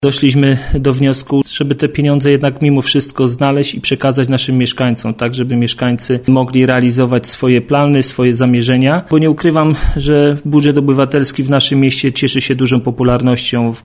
Jak zapewnia Jacek Wiśniewski prezydent Mielca Budżet Obywatelski będzie realizowany mimo trudnej sytuacji finansowej miasta.